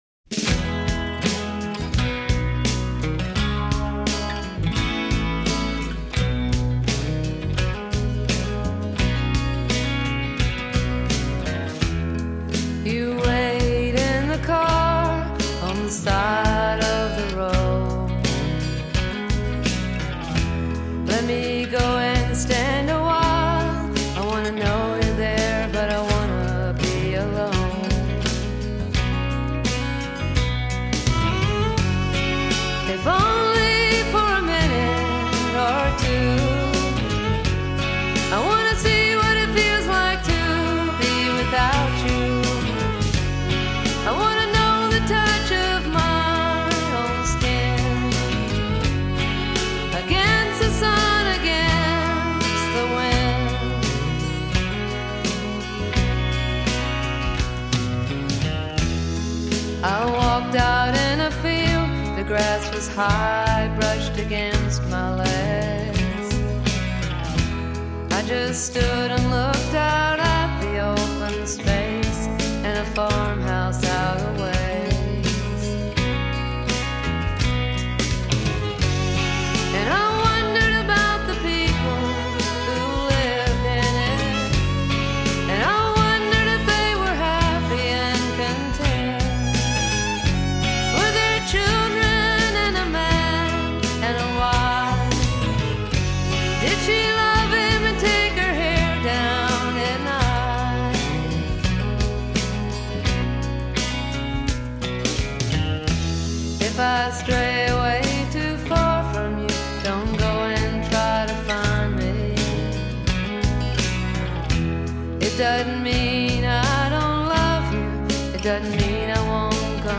By comparison her singing is very pretty on this album.